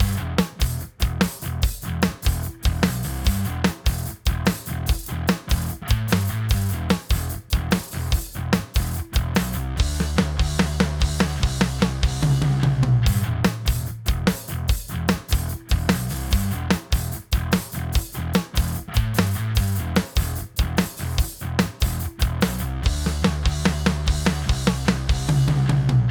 Welcher Preamp/Verzerrer für diesen verzerrten Sound (Marshall/Motörhead Style)
Nun, wenn ich jetzt nicht diesen Amp usw. habe, welchen Verzerrer bzw. Preamp würdet ihr dafür empfehlen? Gespielt ist das ganze mit einem Squier P-Bass.